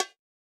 AIR Conga.wav